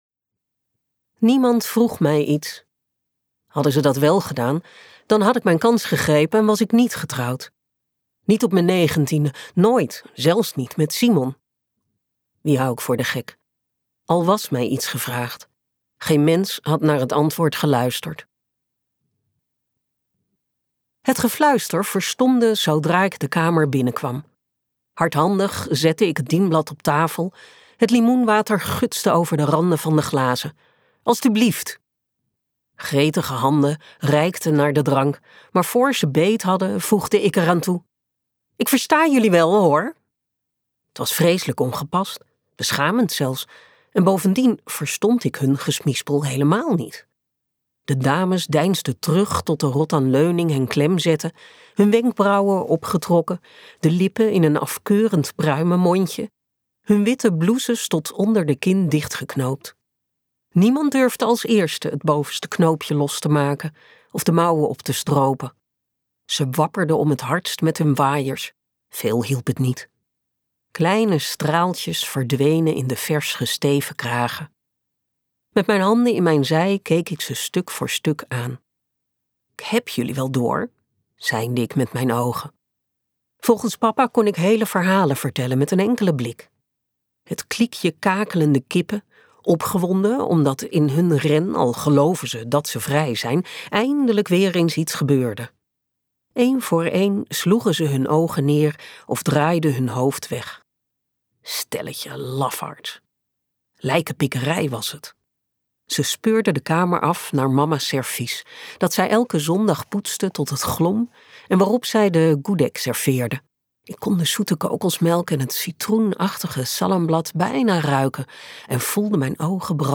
Ambo|Anthos uitgevers - Waar wij altijd geweest zijn luisterboek